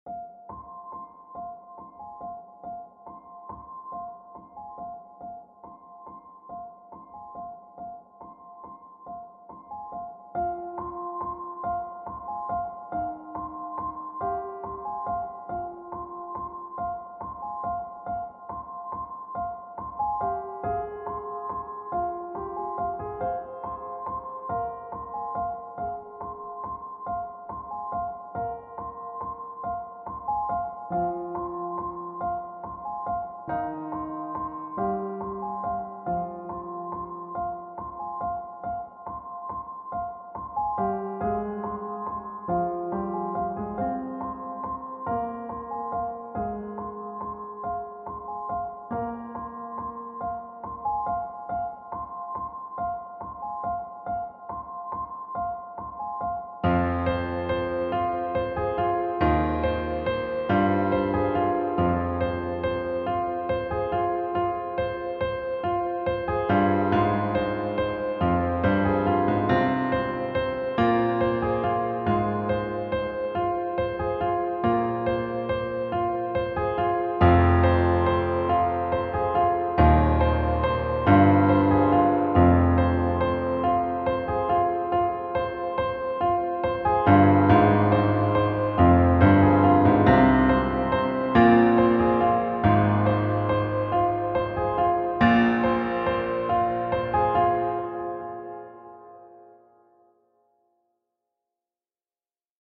piano composition
in a minor key